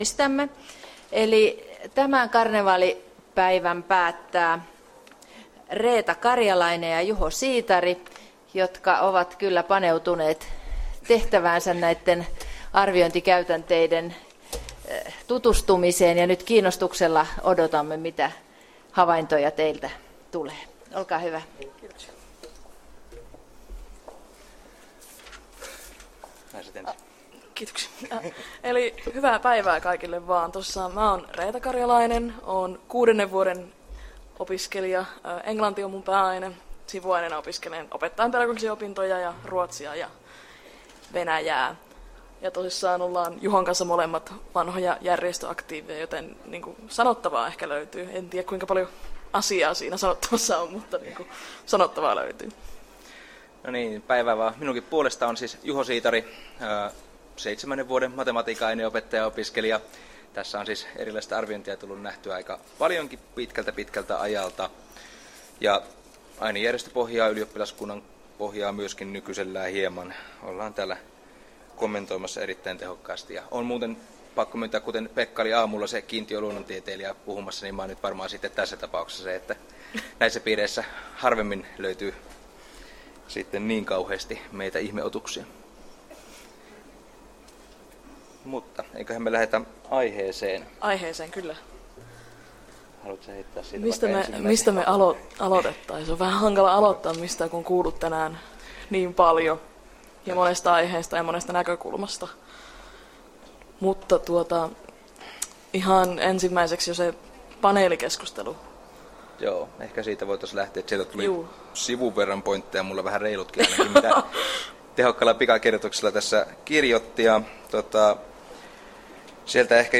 Opiskelijoiden puheenvuoro — Moniviestin
eEducation-karnevaalit 2018